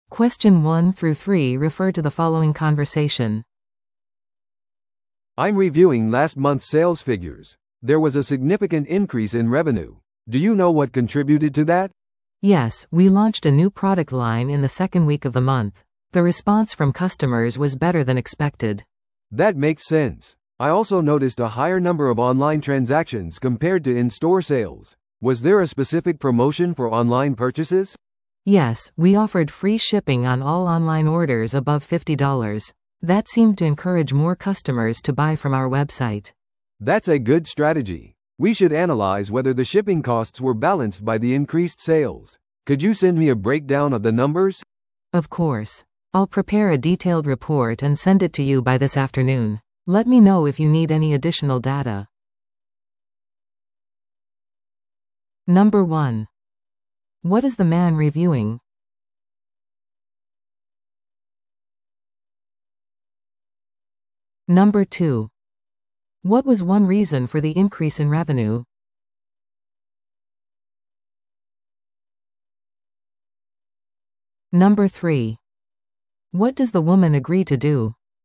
No.1. What is the man reviewing?
No.3. What does the woman agree to do?
Sales report discussion